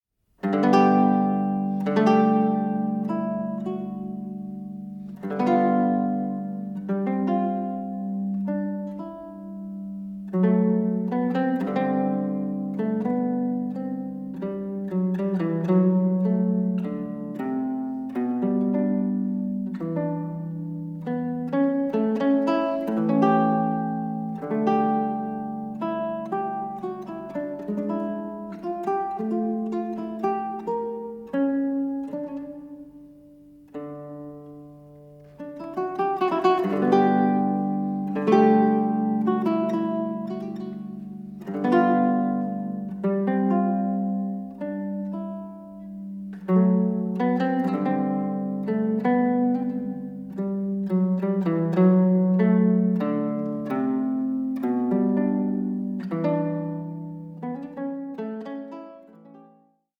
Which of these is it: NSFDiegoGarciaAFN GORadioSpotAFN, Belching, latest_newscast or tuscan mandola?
tuscan mandola